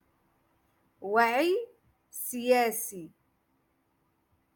Moroccan Dialect- Rotation Six - Lesson Two Three